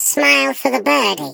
Sfx_tool_spypenguin_vo_take_picture_03.ogg